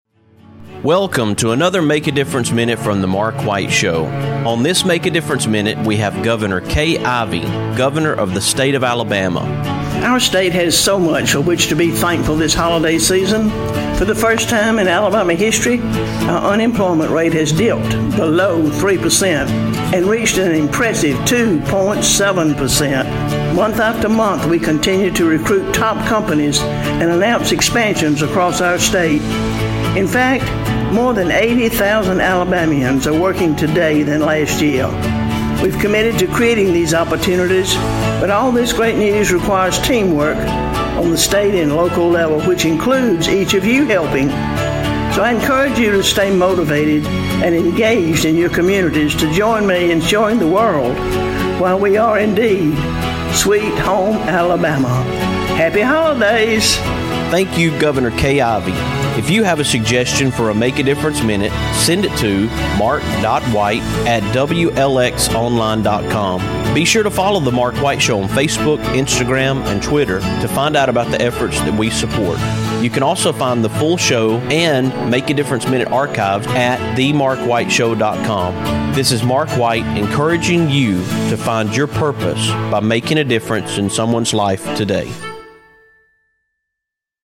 On this Make A Difference Minute, I have Governor Kay Ivey, governor of the state of Alabama, with a positive message about employment in the state of Alabama and a holiday greeting.